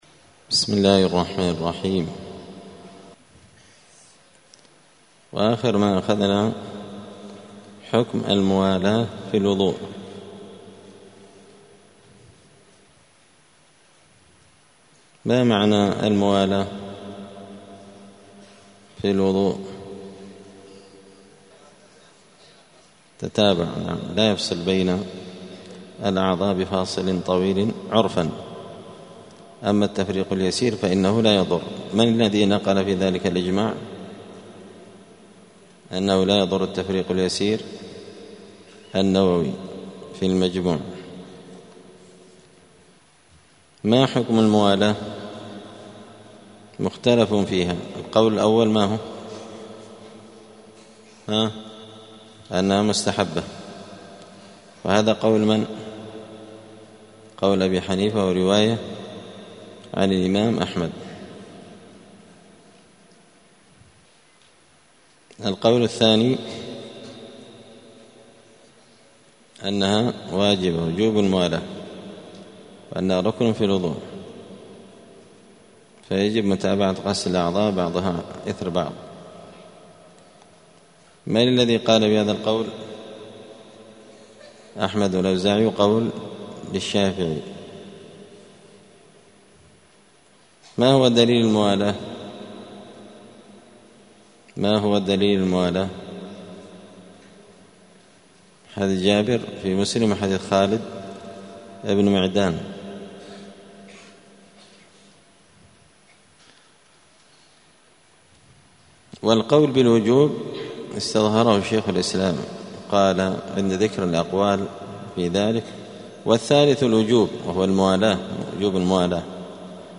دار الحديث السلفية بمسجد الفرقان قشن المهرة اليمن
*الدرس الخامس والثلاثون [35] {باب صفة الوضوء حكم المولاة في الوضوء}*